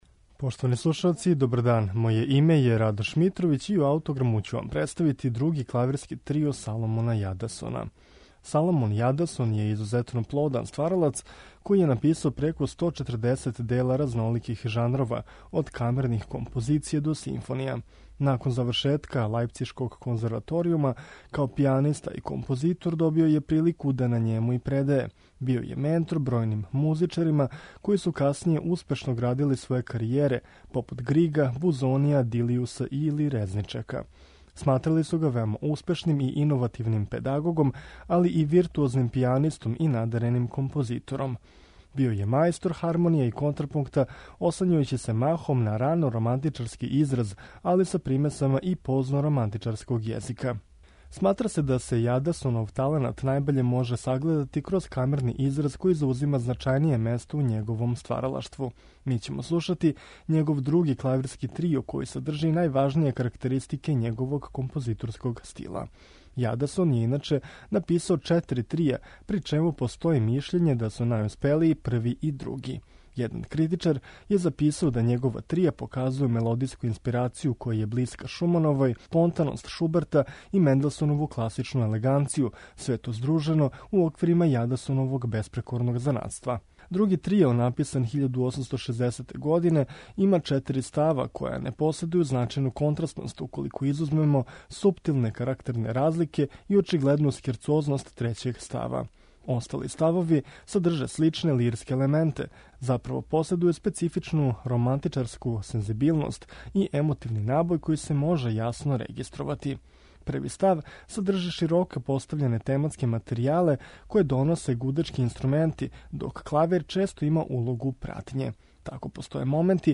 Дело у интерпретацији трија Сиријус